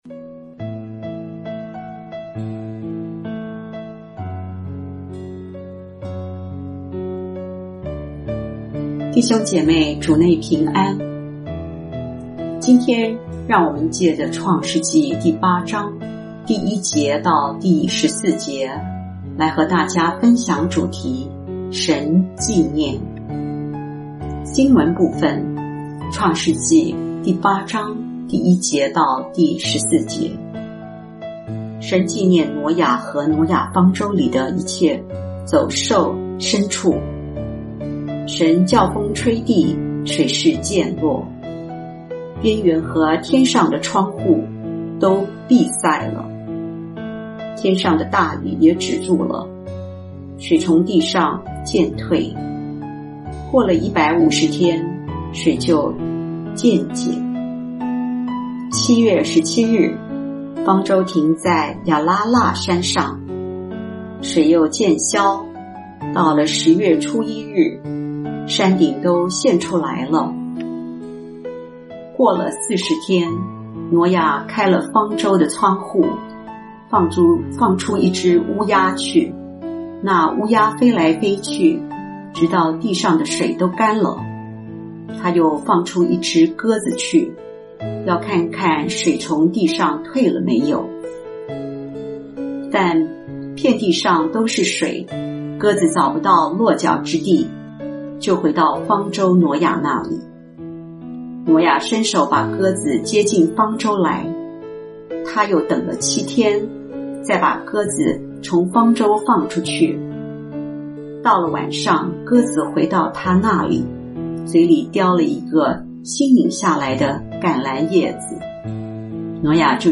牧長同工分享-2：神記念